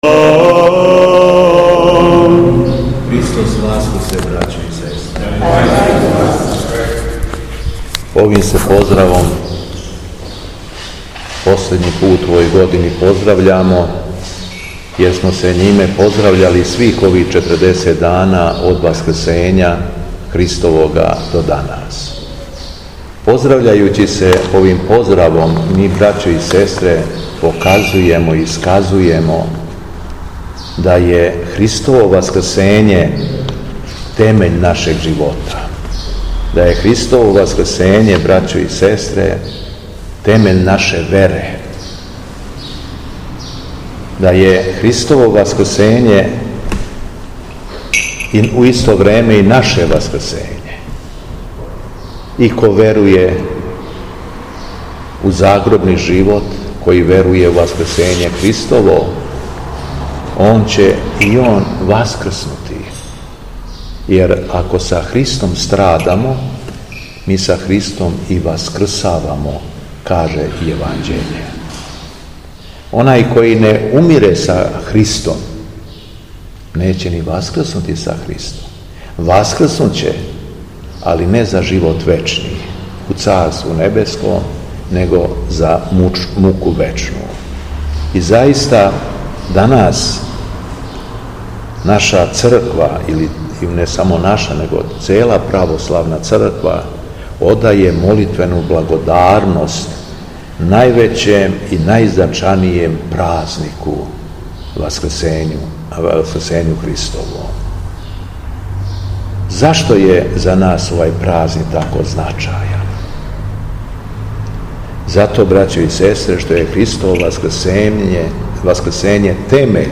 Беседа Његовог Преосвештенства Епископа шумадијског г. Јована
По прочитаном јеванђелском зачалу епископ се обратио народу: